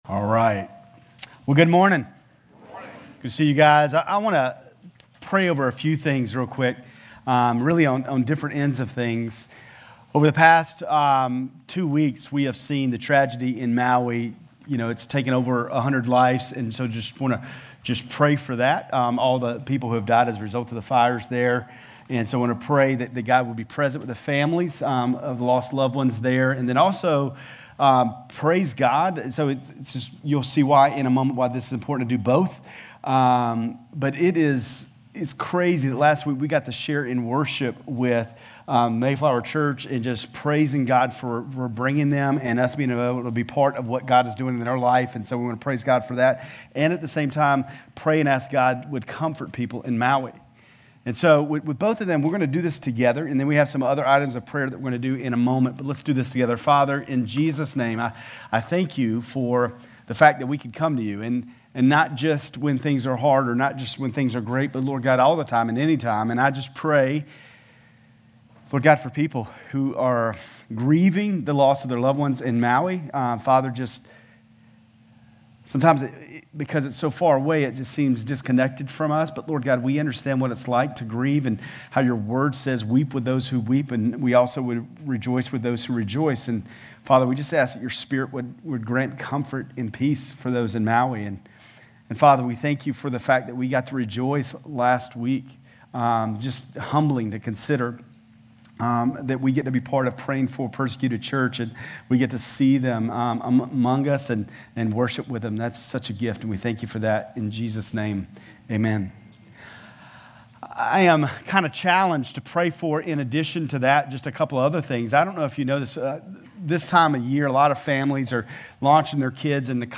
GCC-Lindale-August-20-Sermon.mp3